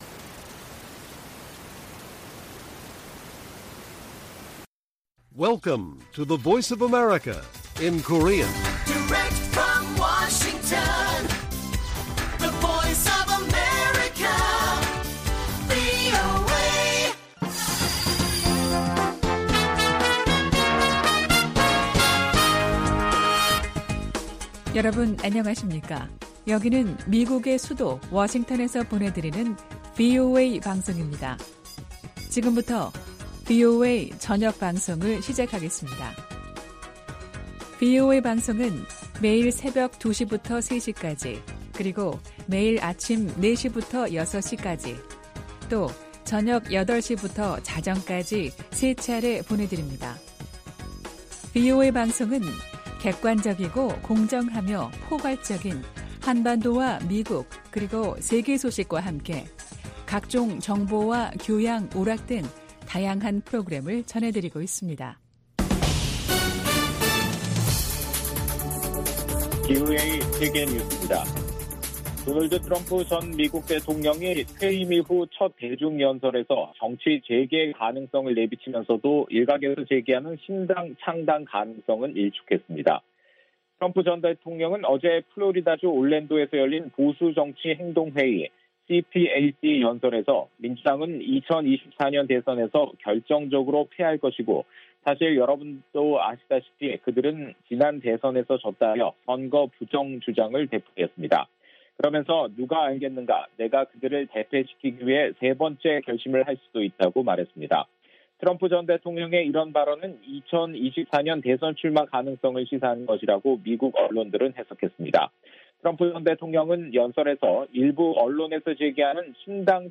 VOA 한국어 간판 뉴스 프로그램 '뉴스 투데이' 1부 방송입니다.